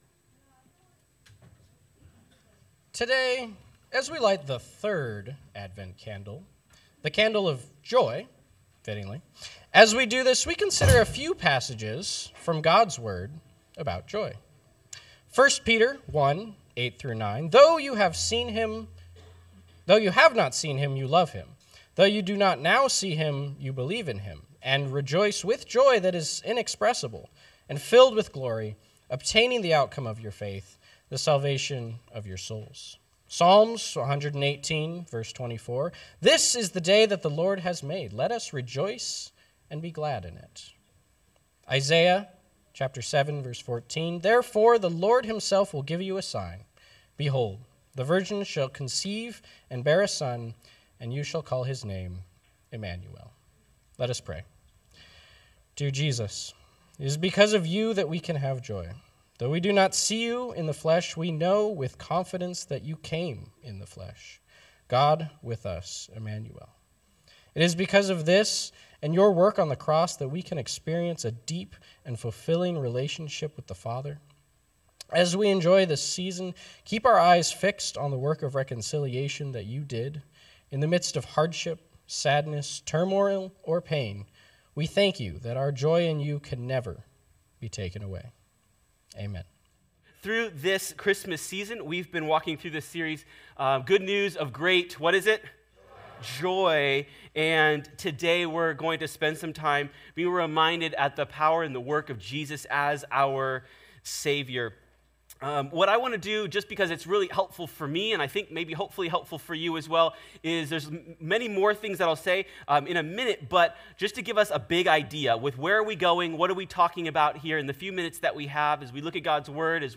This third Sunday of Advent we light the candle of joy, which is also the theme in our Christmas series.